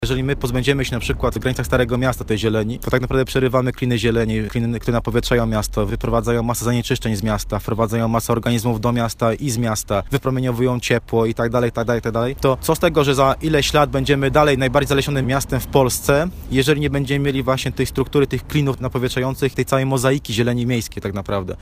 na konferencji prasowej